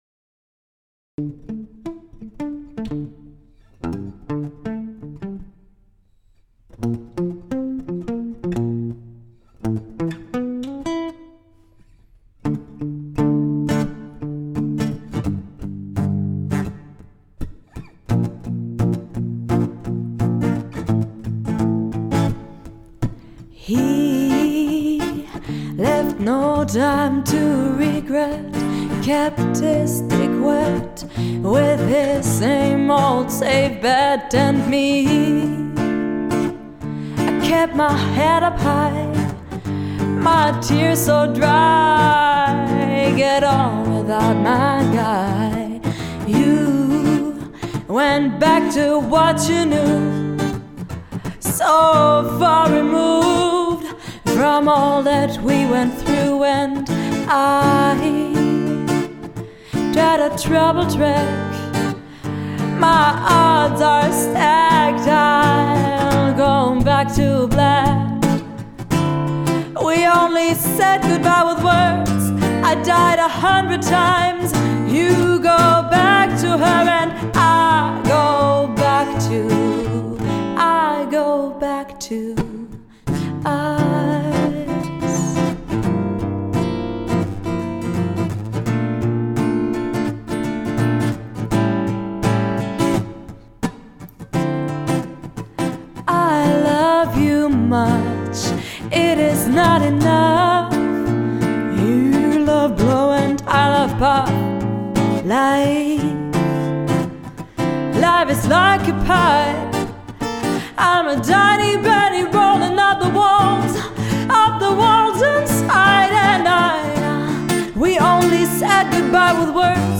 Genre: Jazz/Pop.